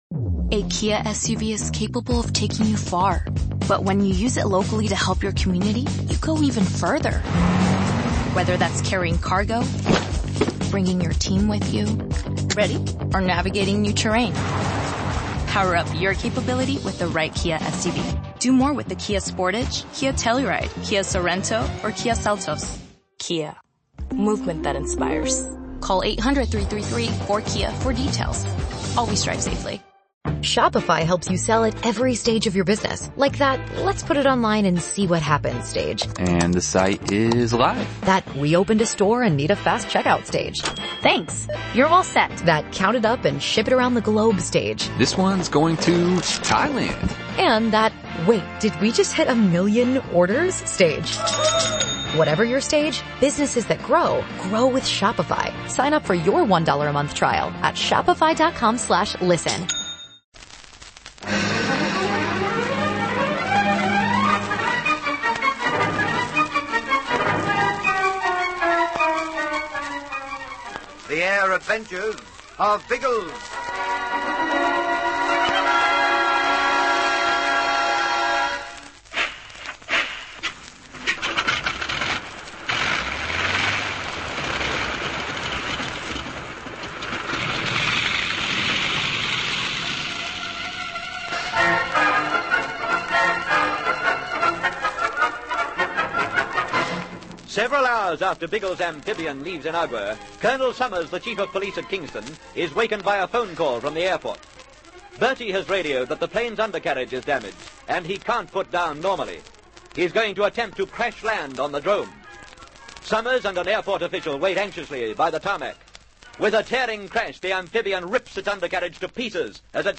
The Air Adventures of Biggles was a popular radio show that ran for almost a decade in Australia, from 1945 to 1954.
Biggles and his trusty companions, Ginger Hebblethwaite and Algy Lacey, soared through the skies in a variety of aircraft, from biplanes to jet fighters, taking on villains, rescuing damsels in distress, and generally having a whale of a time. The show was known for its exciting sound